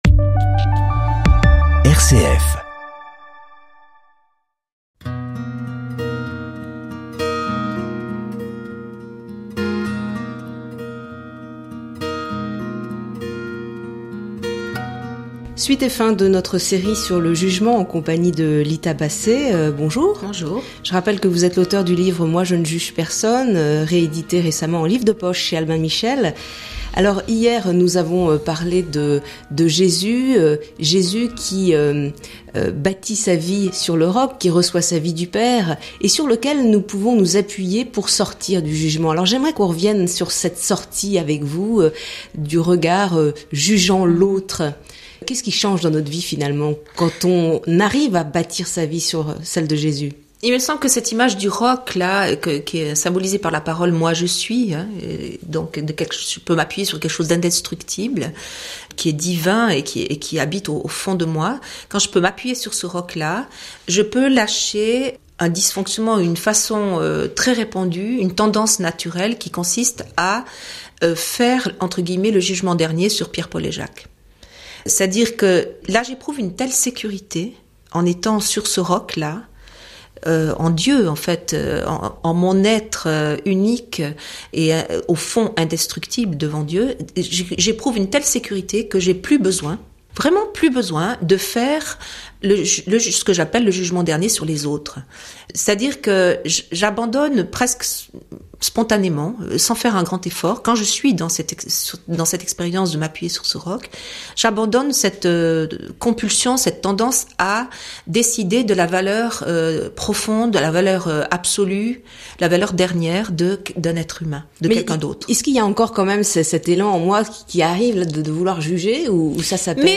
théologienne protestante Écouter Partager